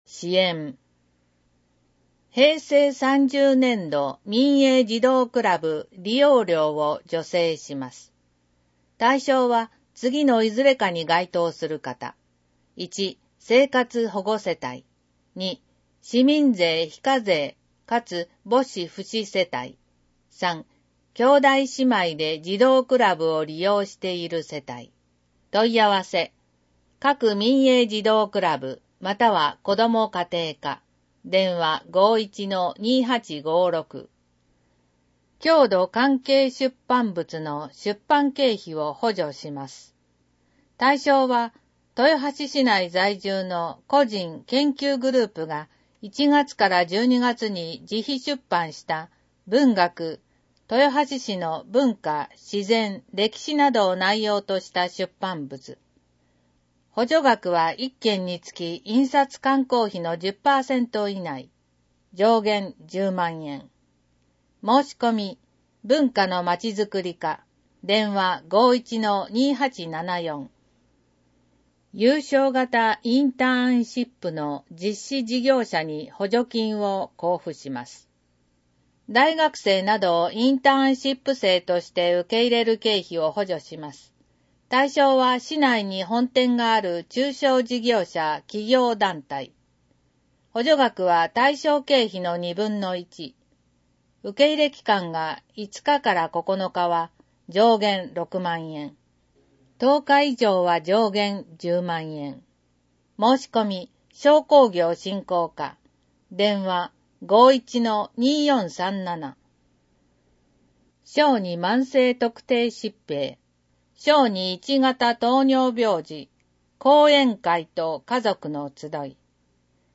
• 「広報とよはし」から一部の記事を音声でご案内しています。視覚障害者向けに一部読み替えています。
（音声ファイルは『音訳グループぴっち』提供）